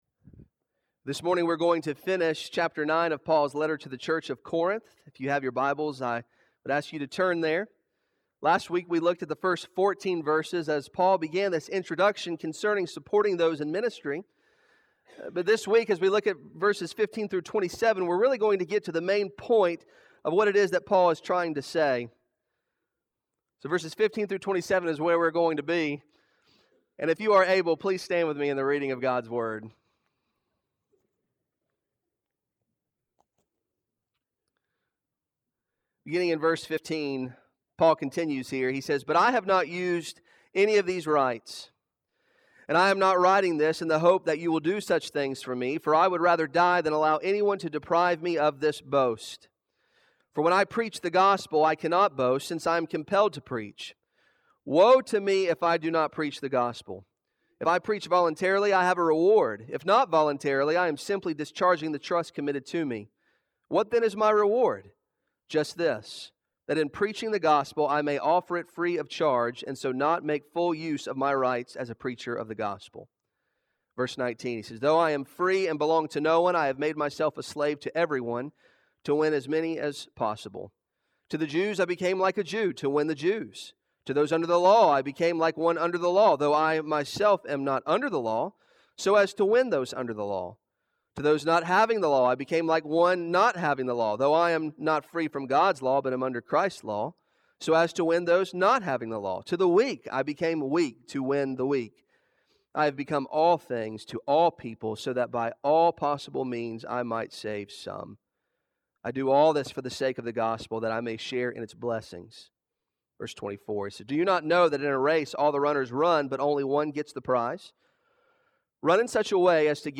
Arlington Baptist Church Sermons